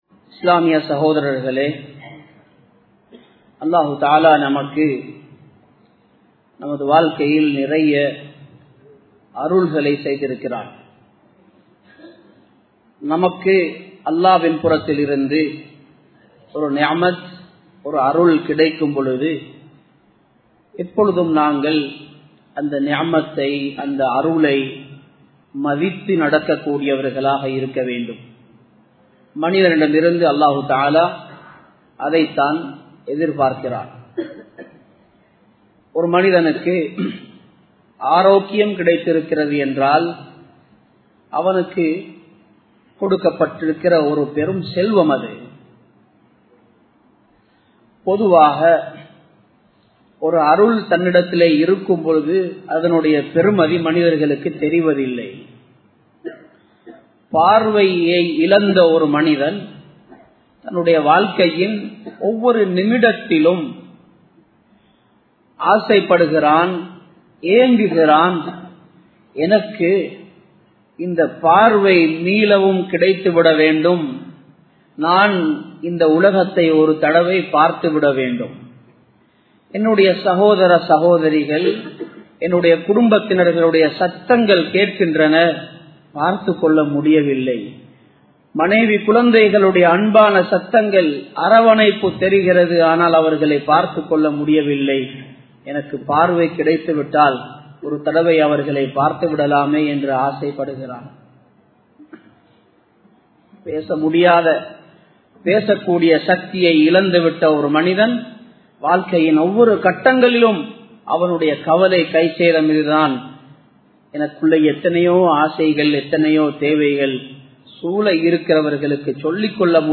Niumaththuhal Marumaiel Visaarikkapadum (நிஃமத்துகள் மறுமையில் விசாரிக்கப்படும்) | Audio Bayans | All Ceylon Muslim Youth Community | Addalaichenai
Colombo 11, Samman Kottu Jumua Masjith (Red Masjith)